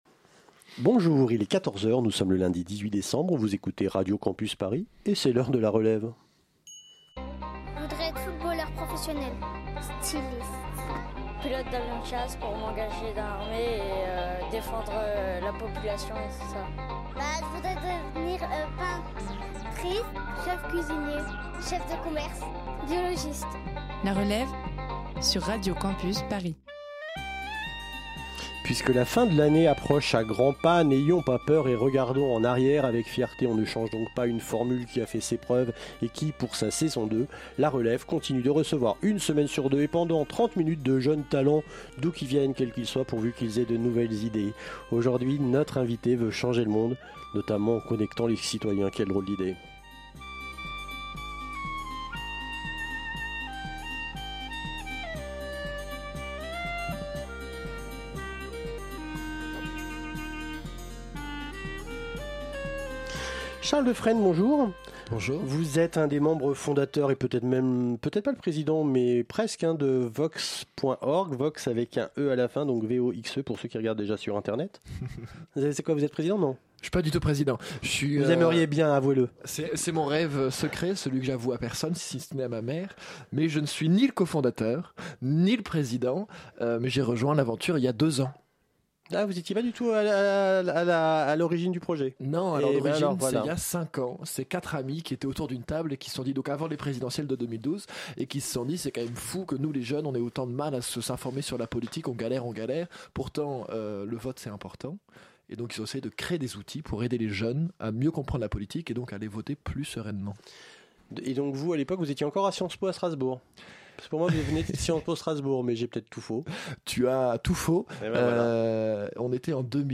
Pendant l'émission vous avez écouté Le discours d' Emmanuel Macron le soir de son élection au Louvre à Paris Réalisation